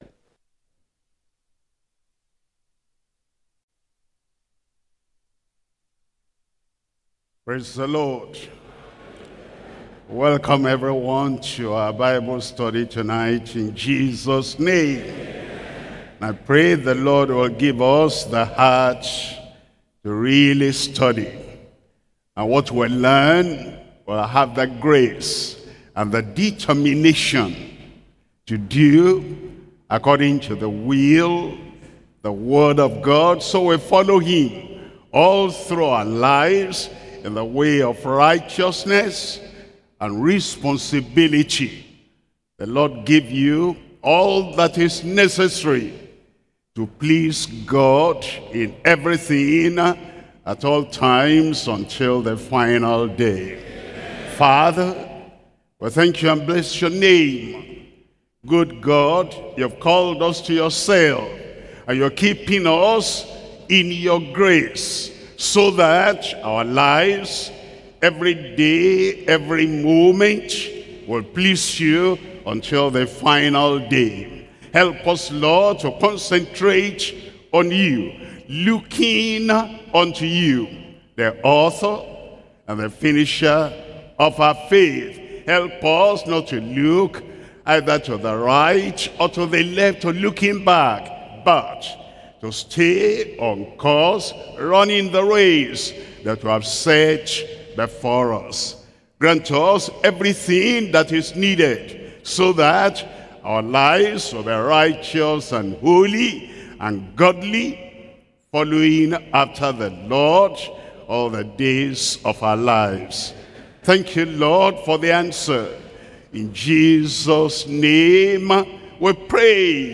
Sermons - Deeper Christian Life Ministry
Bible Study